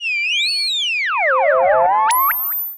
Theremin_Swoop_12.wav